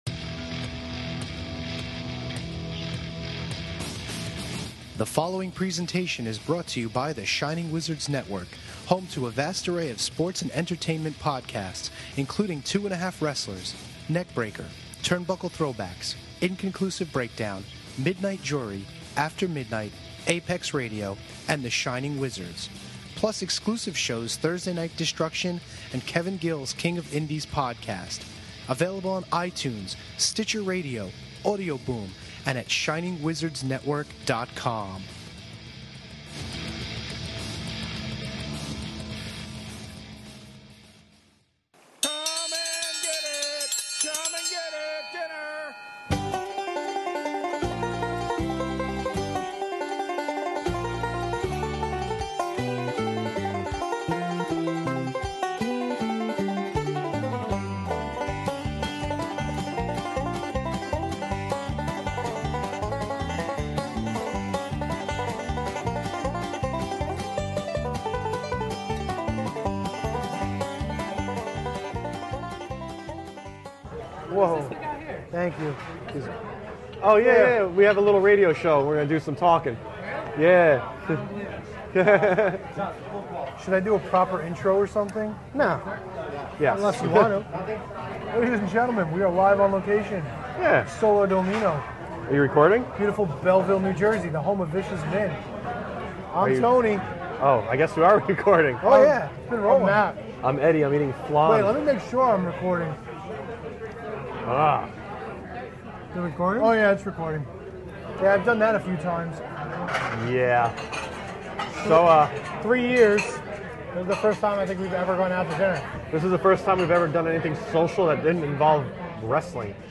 They decided to take a test run using some lapel mics and did some talk over dinner.